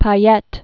(pä-yĕt, pā-, pă-lĕt)